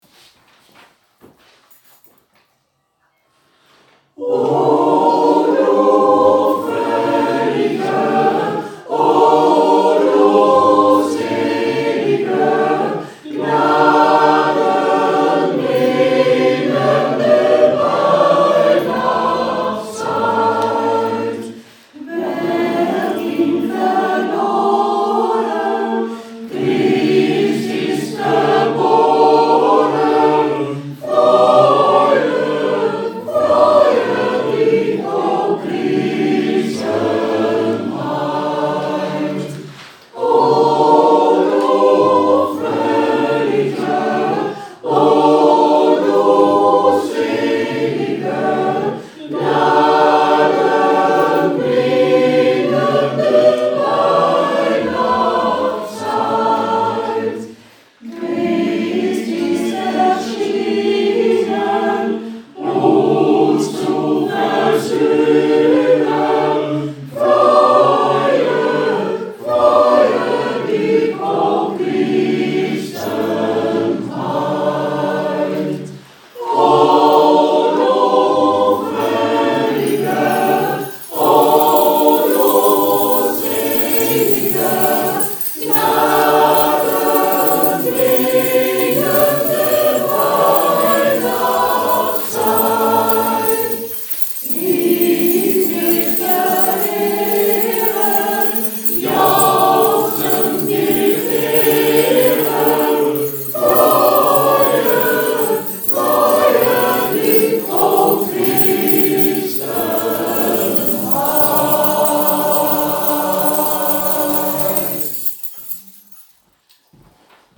Het Nederlands Genootschap van Sint Jacob heeft een eigen pelgrimskoor: El Orfeón Jacobeo. Het koor bestaat uit 40 leden.